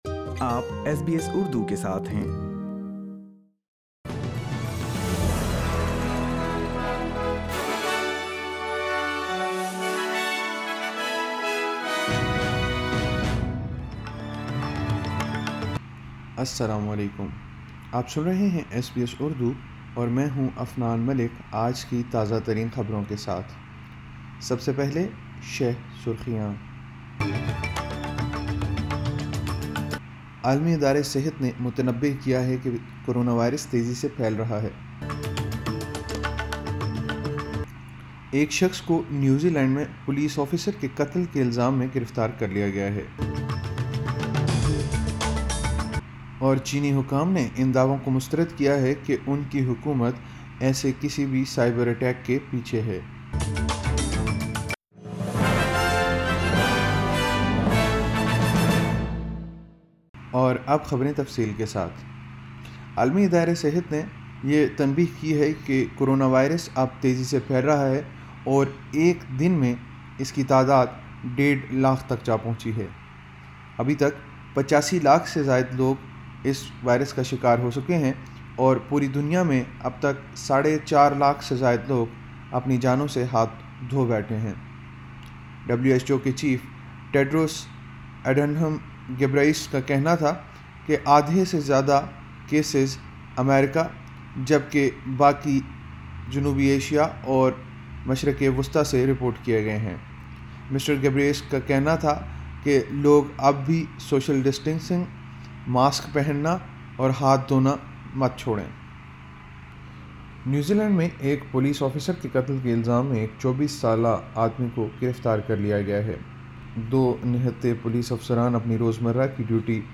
SBS Urdu News 20 June 2020